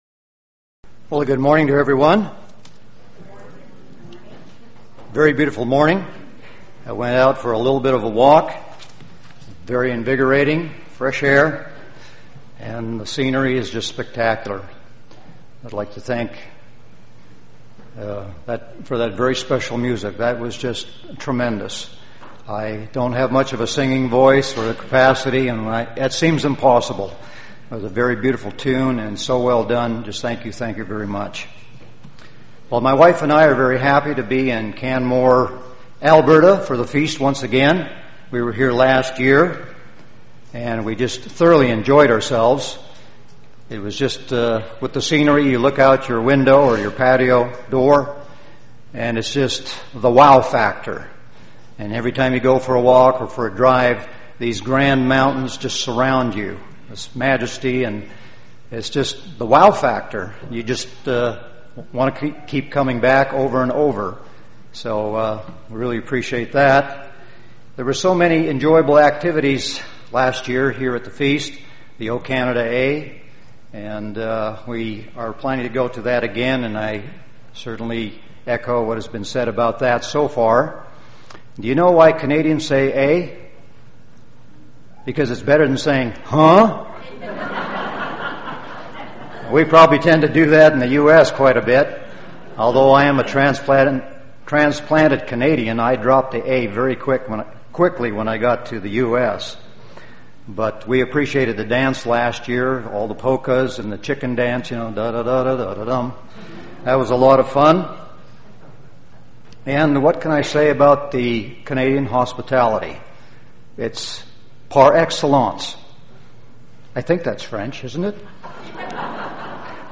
This sermon was given at the Canmore, Alberta 2013 Feast site.